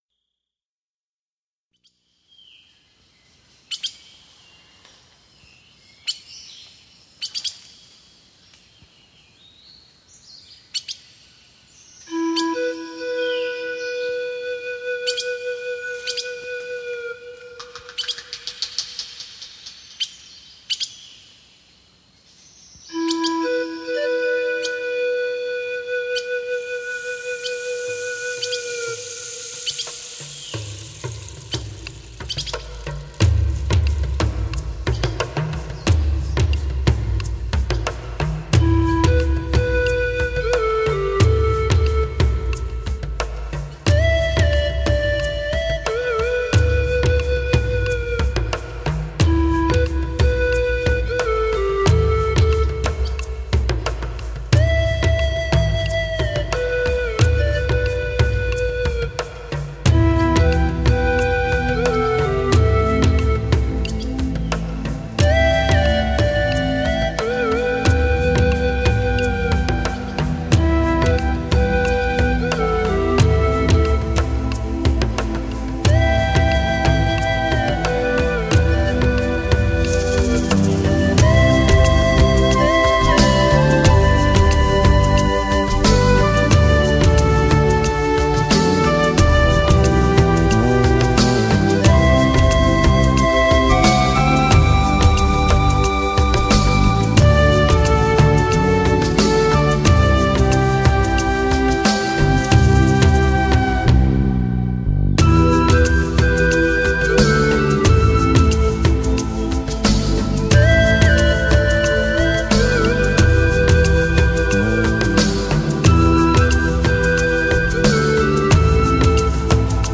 音樂風格：純音樂